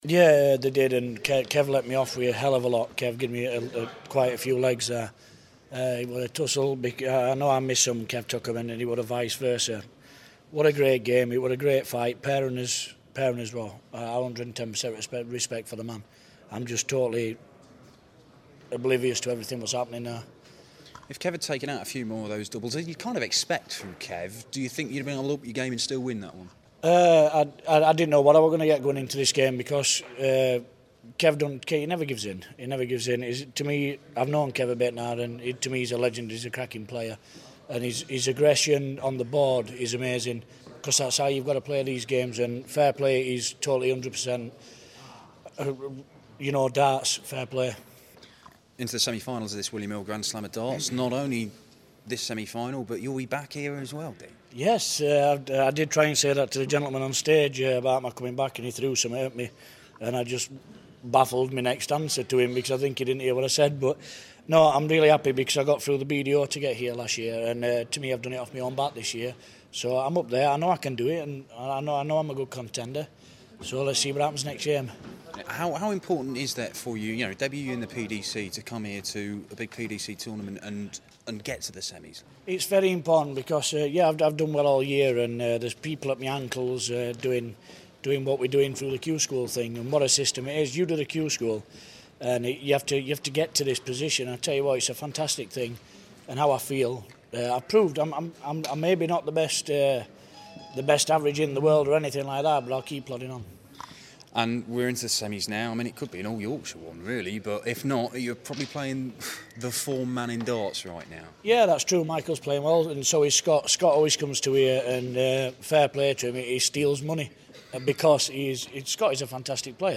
William Hill GSOD - Winstanley Interview (QF)